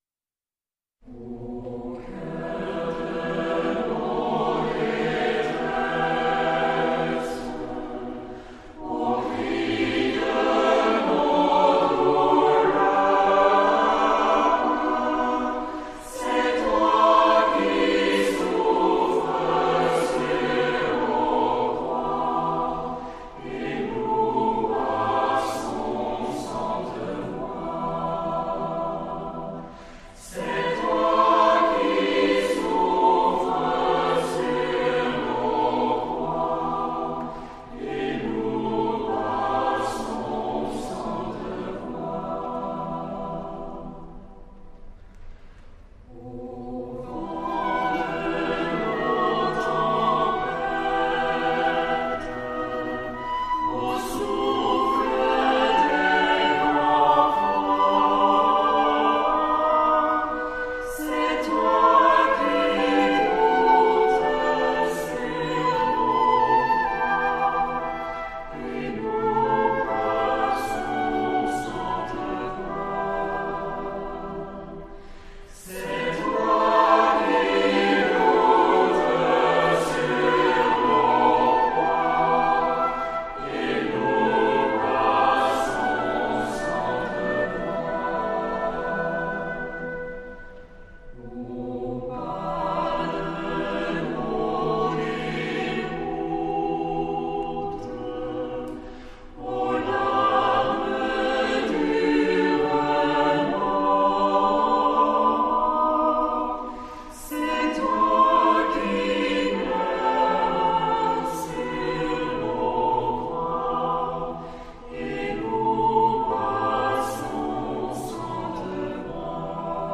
Vous pouvez entrecouper avec le chant « Au coeur de nos détresses »